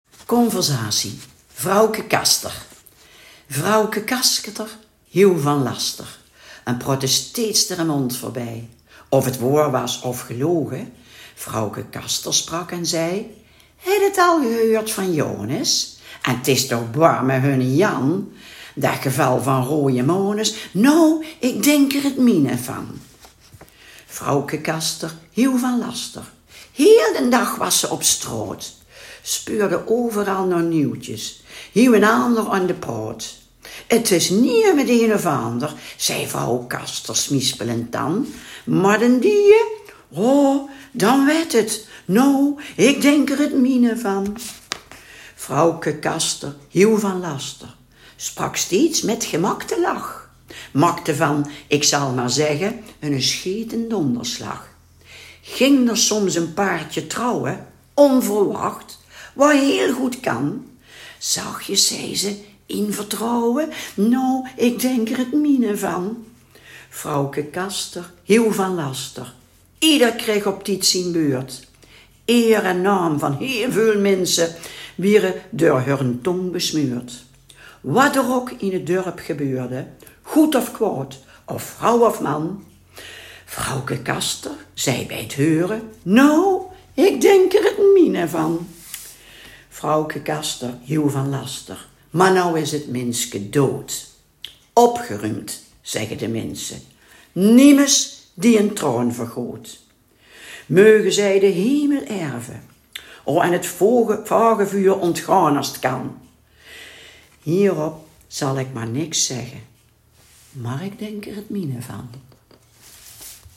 De inwoners van Overasselt maken onder elkaar gebruik van het Overasselts dialect.